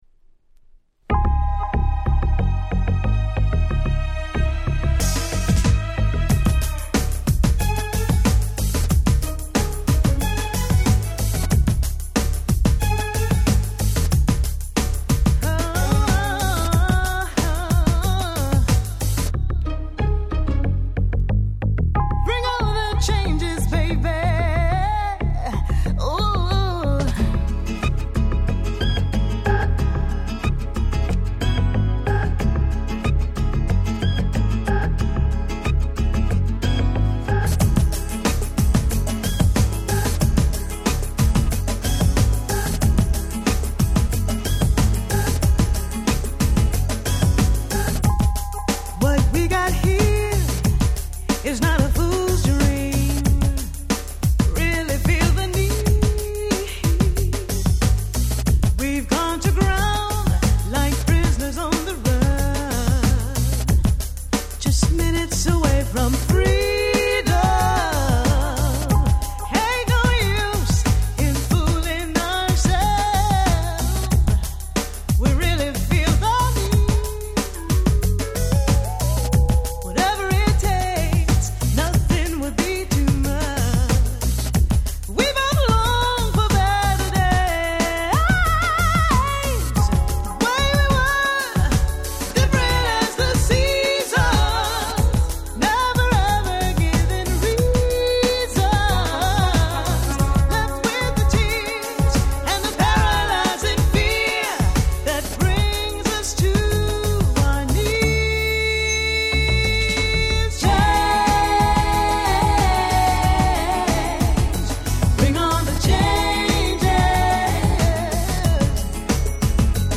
92' Acid Jazz Classics !!